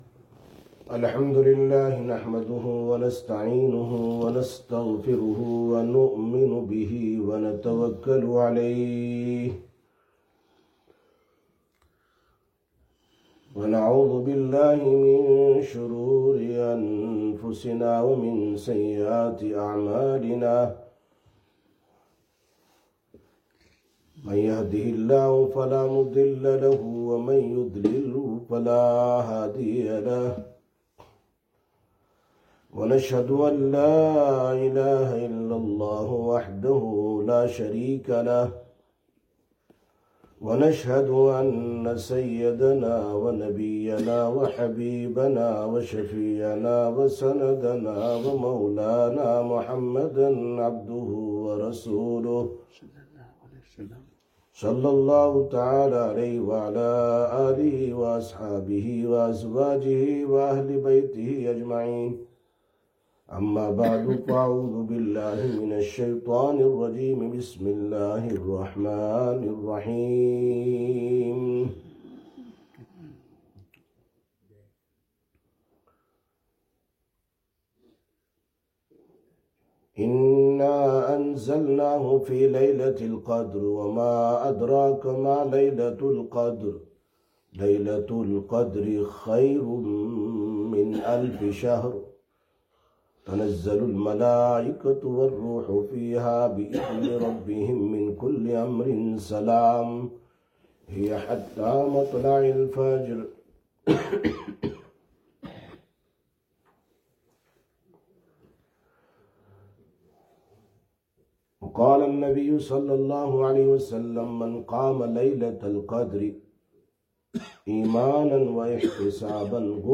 06/03/2026 Jumma Bayan, Masjid Quba